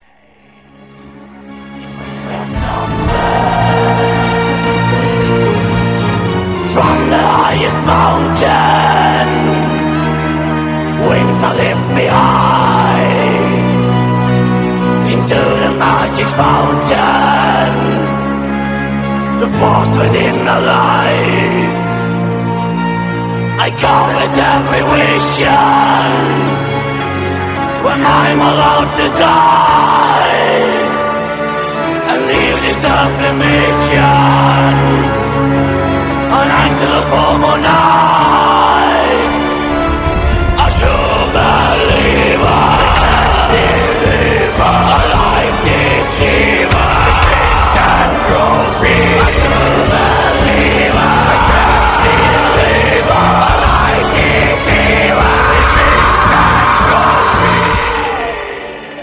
Black Metal Sound Files